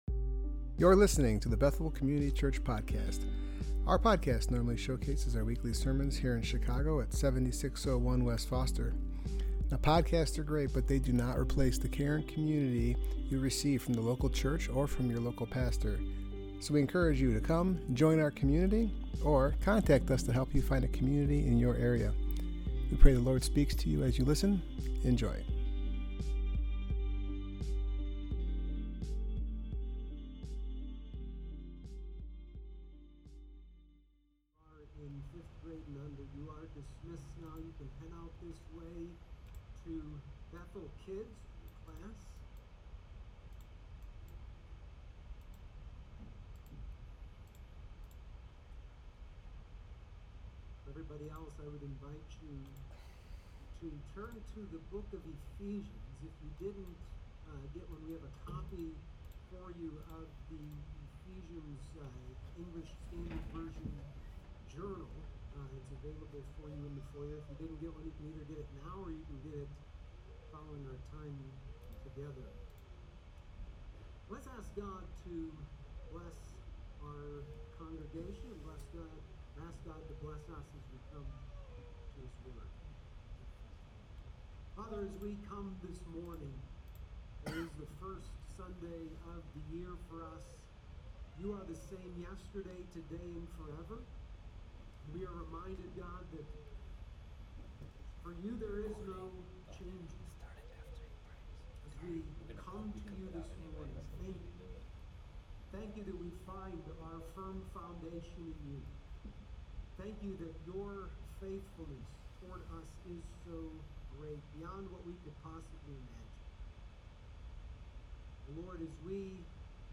Passage: Ephesians 1:1-2; 6:21-24 Service Type: Worship Gathering